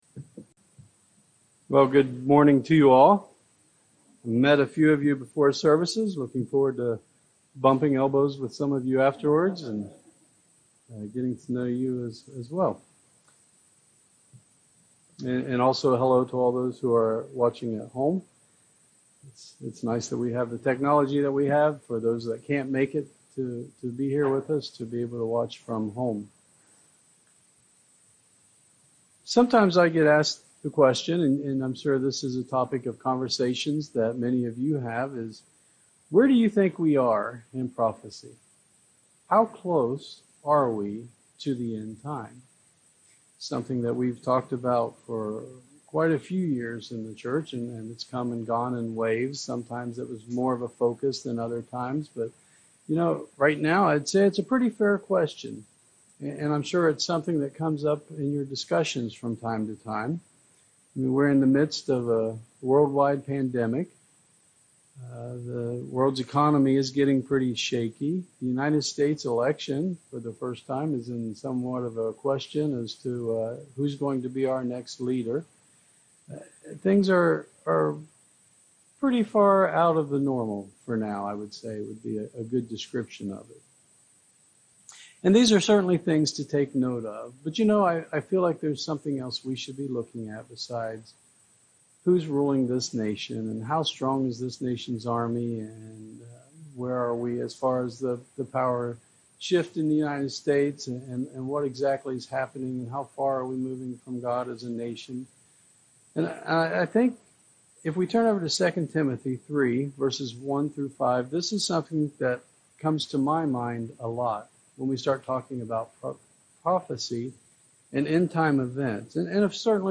Sermons
Given in Charlotte, NC Columbia, SC Hickory, NC